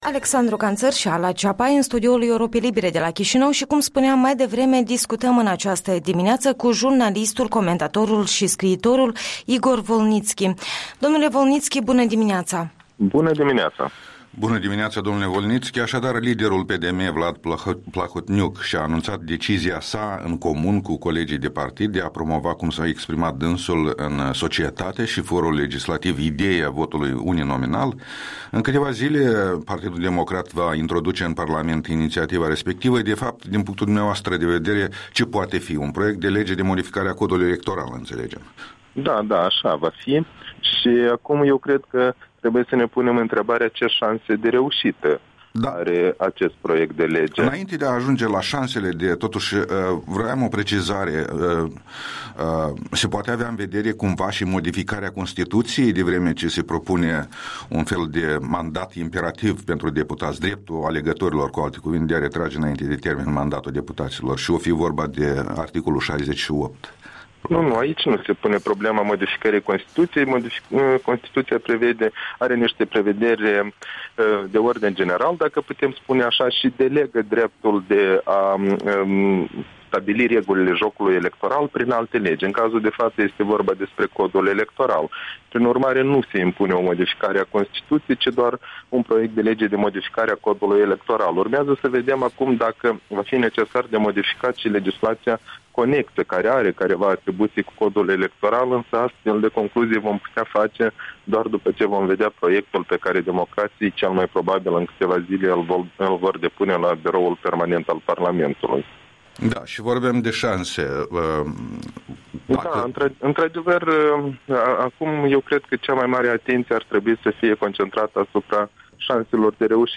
Interviul dimineții cu jurnalistul și comentatorul politic de la Chișinău.